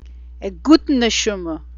Gutte neshumah (goot-teh nesh-uh-mah): a good soul. A decent person with a good heart.